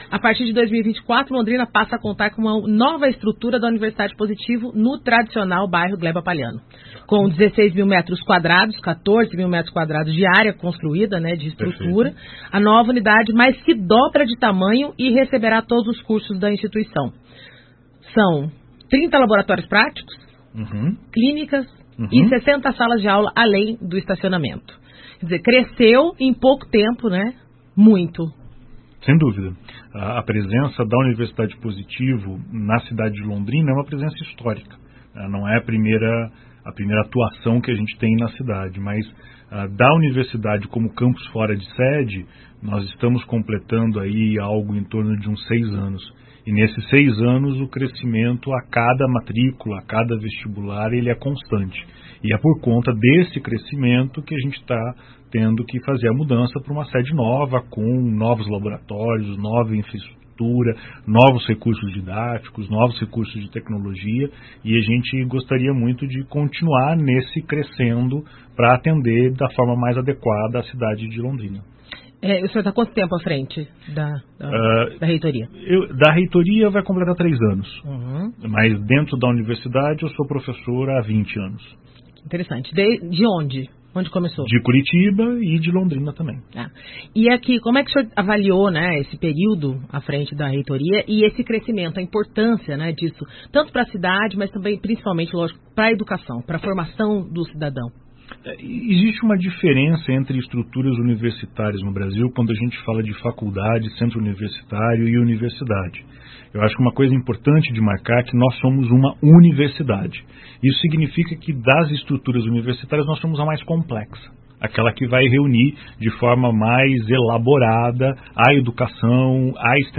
Áudio: Representantes da Universidade Positivo participam de entrevista nos estúdios da Paiquerê FM 98.9